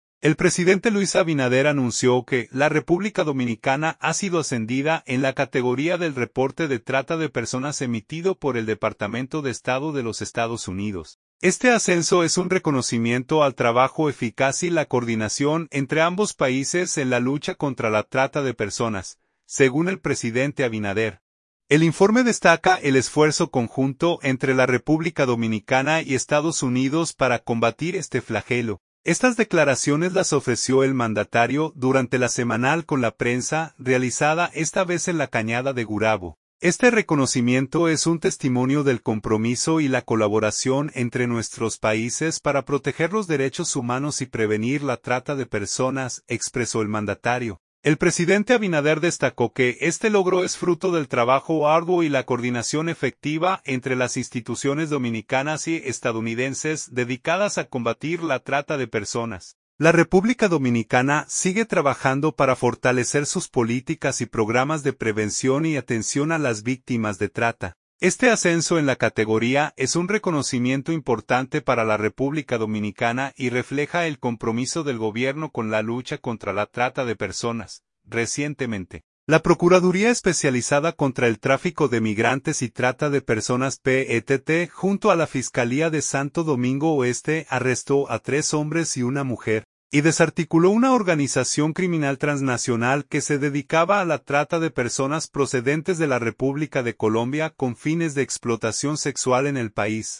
Estas declaraciones las ofreció el mandatario durante LA Semanal con la Prensa, realizada esta vez en la cañada de Gurabo.